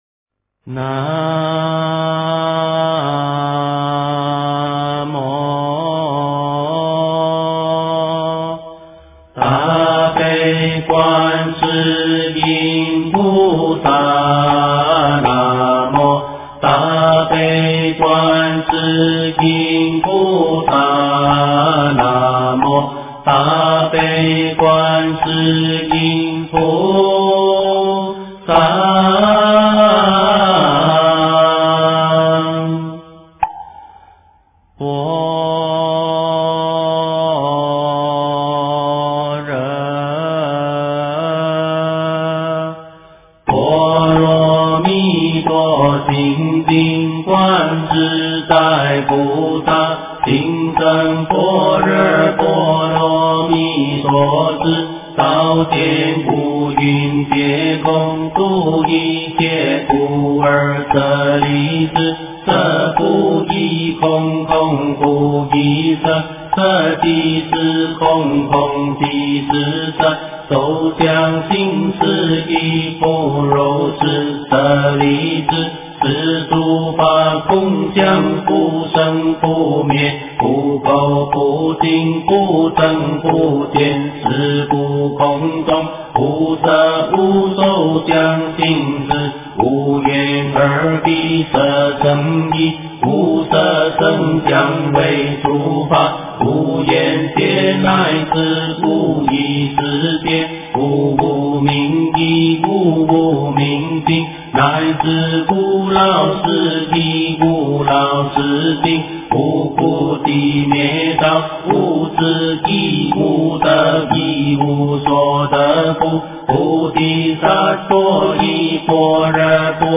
般若波罗蜜多心经--诵念快版
般若波罗蜜多心经--诵念快版 经忏 般若波罗蜜多心经--诵念快版 点我： 标签: 佛音 经忏 佛教音乐 返回列表 上一篇： 早课--临济宗净觉山光德寺 下一篇： 杨枝净水赞--佛音 相关文章 华严字母一合--如是我闻 华严字母一合--如是我闻...